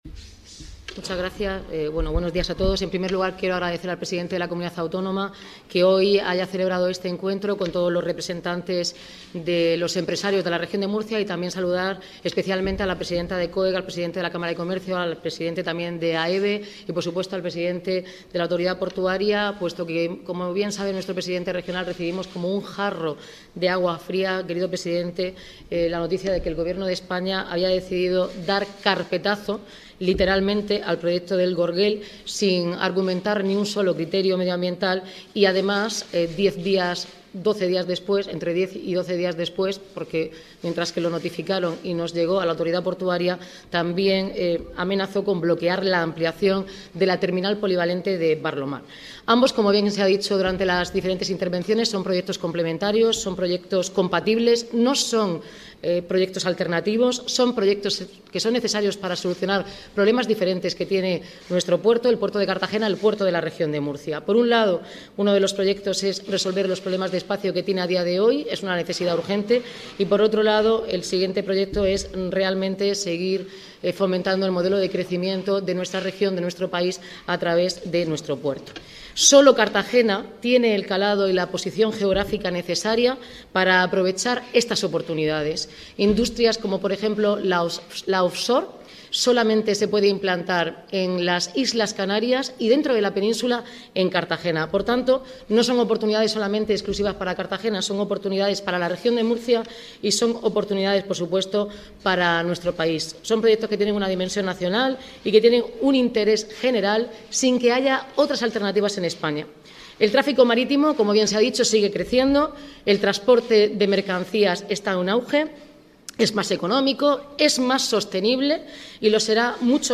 Enlace a Declaraciones de Noelia Arroyo y Fernando López Miras